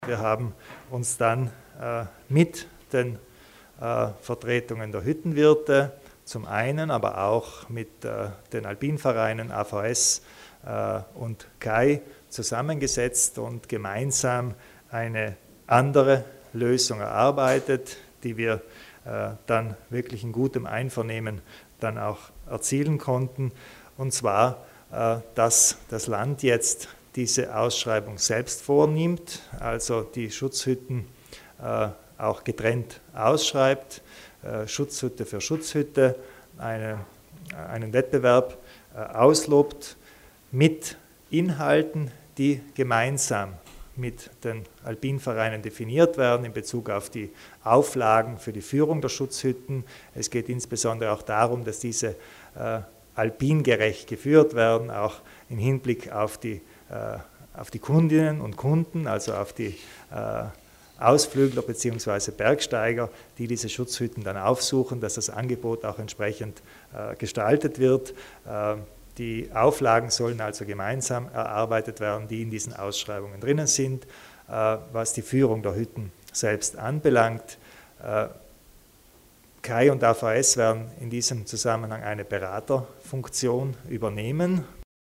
Landeshauptmann Kompatscher zu den Neuigkeiten bei den Schutzhütten